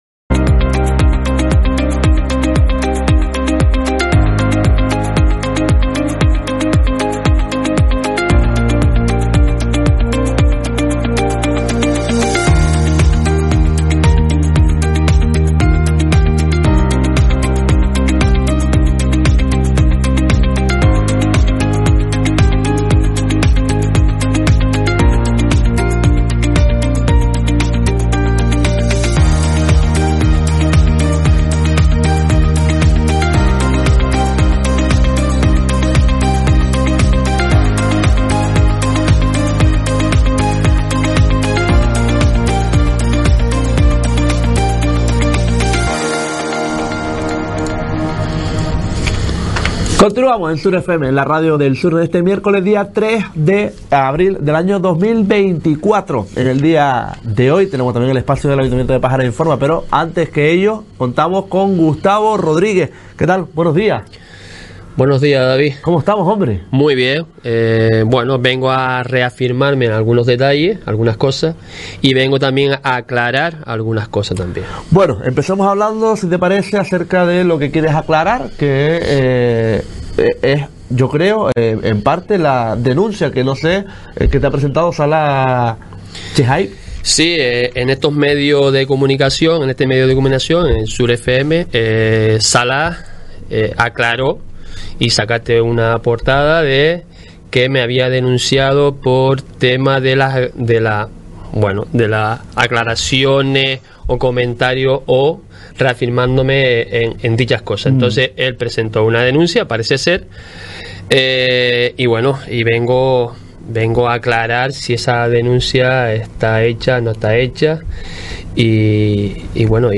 En la entrevista quiso decir alto y claro que «el…